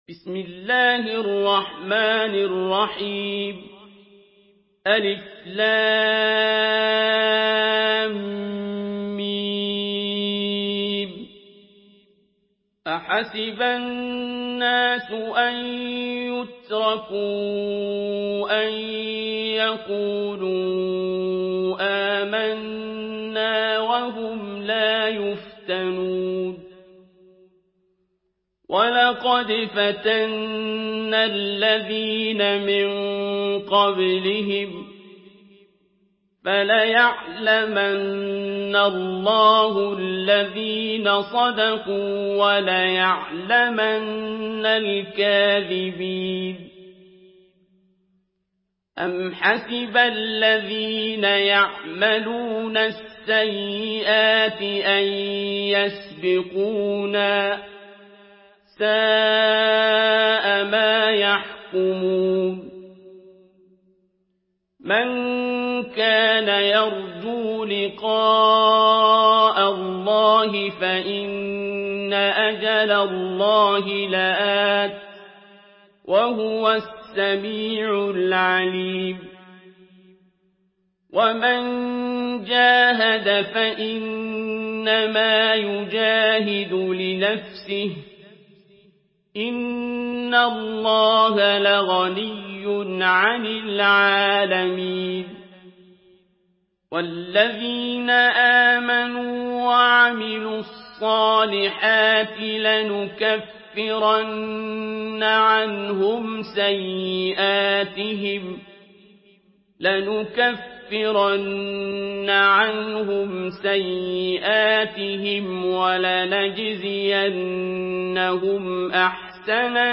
Surah আল-‘আনকাবূত MP3 by Abdul Basit Abd Alsamad in Hafs An Asim narration.
Murattal Hafs An Asim